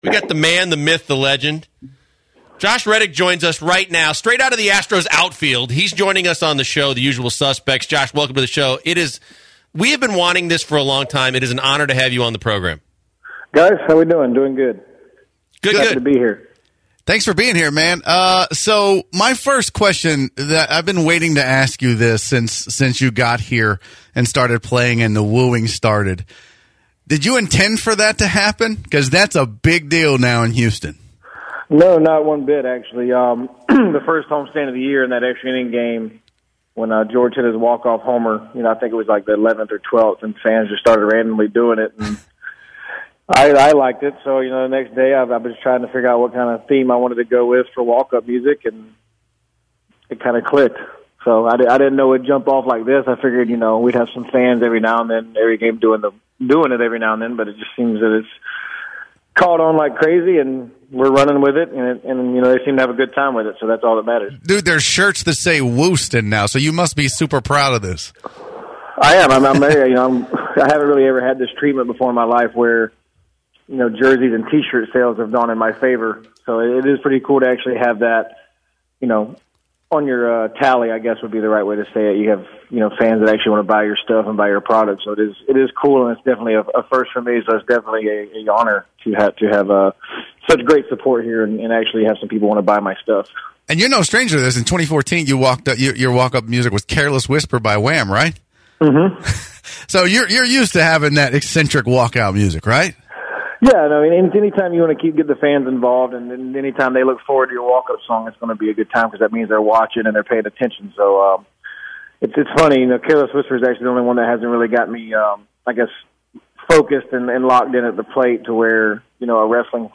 Josh Reddick interview with The Usual Suspects